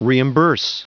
Prononciation du mot reimburse en anglais (fichier audio)
Prononciation du mot : reimburse